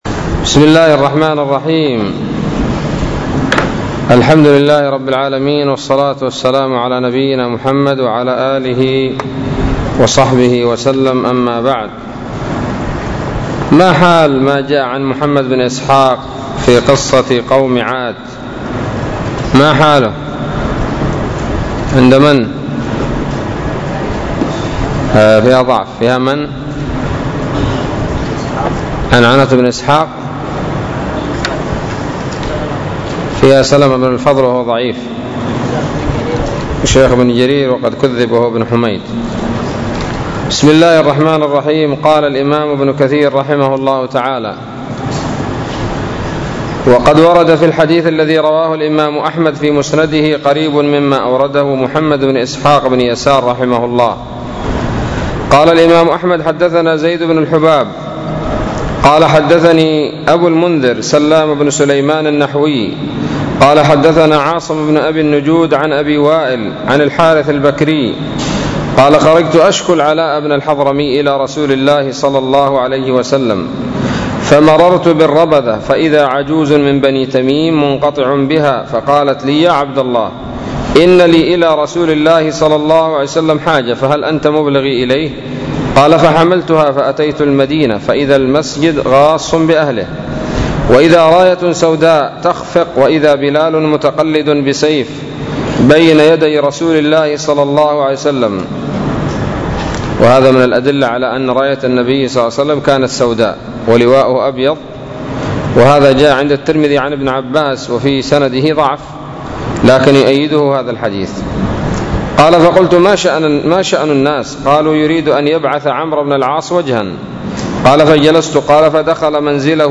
007 سورة الأعراف الدروس العلمية تفسير ابن كثير دروس التفسير